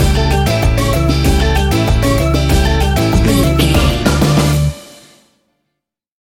Aeolian/Minor
steelpan
worldbeat
drums
percussion
brass
guitar